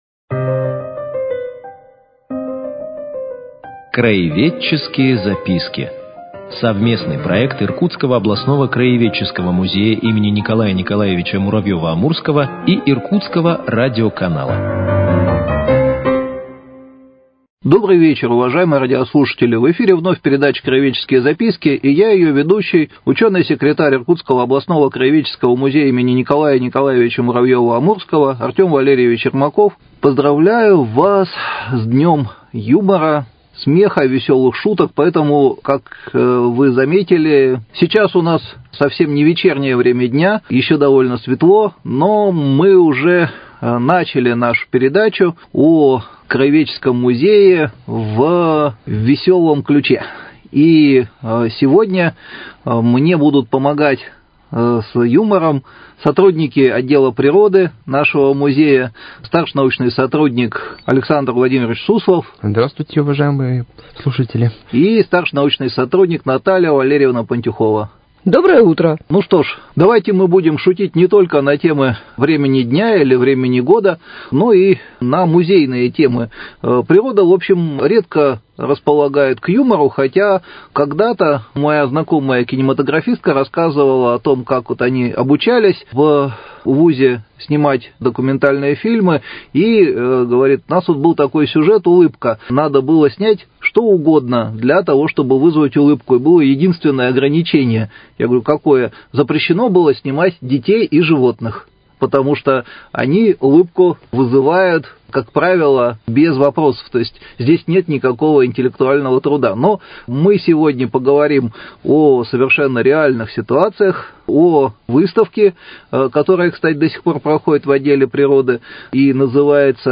Цикл передач – совместный проект Иркутского радиоканала и Иркутского областного краеведческого музея им. Н.Н.Муравьёва - Амурского.